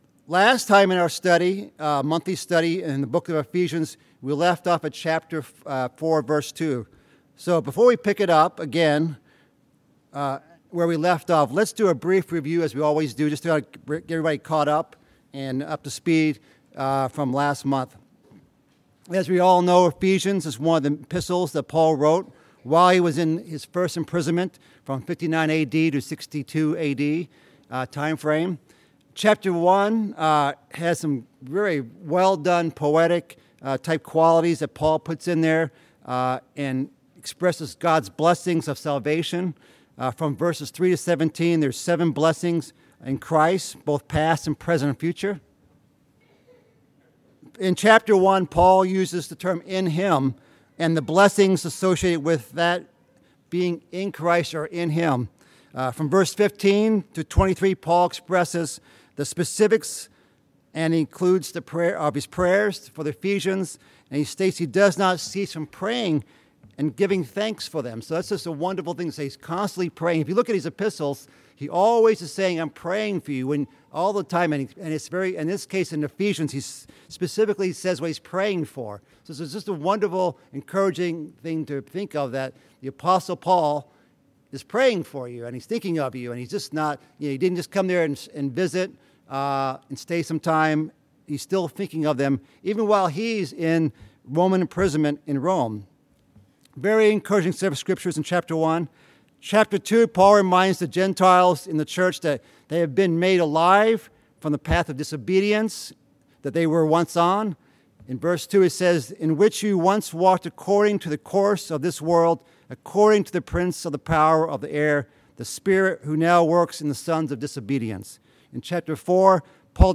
Bible Study: Ephesians